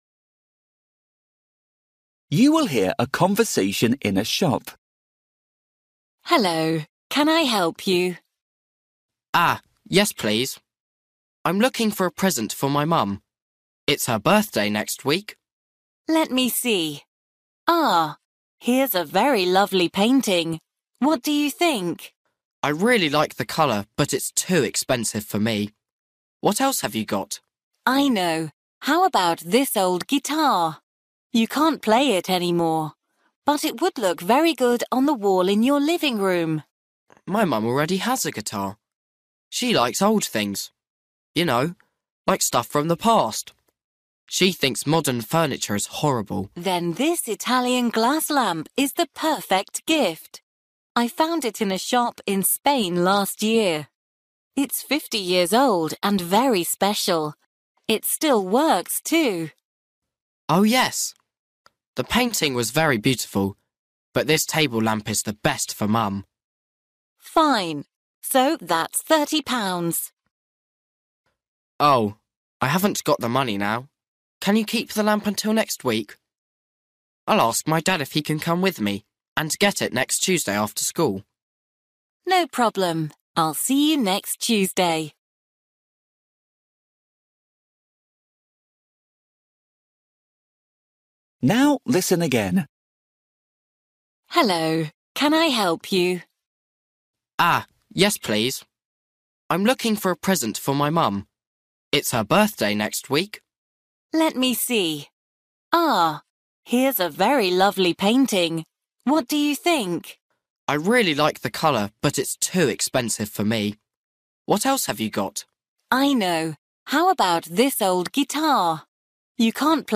Listening: A conversation in a shop
Một cuộc trò chuyện trong một cửa hàng
You will hear a conversation in a shop.